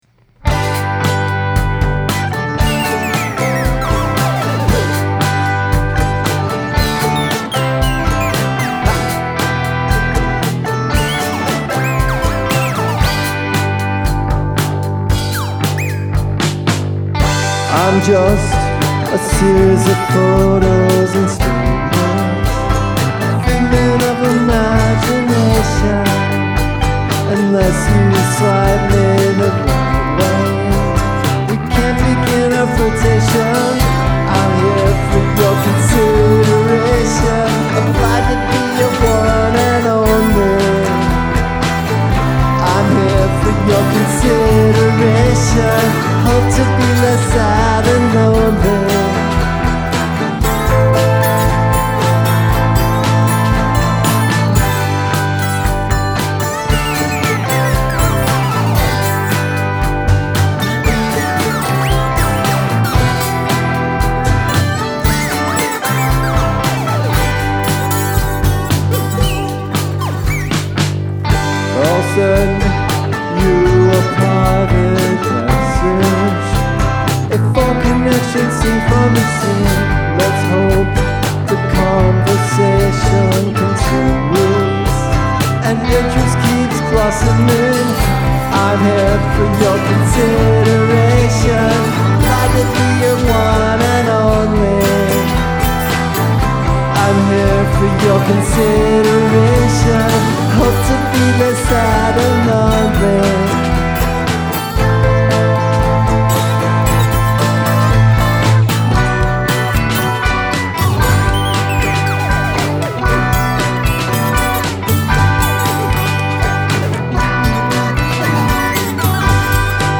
Electric Mandolin
electric guitar
Electric Piano
electric Bass
acoustic drums
acoustic Mandolin
acoustic vox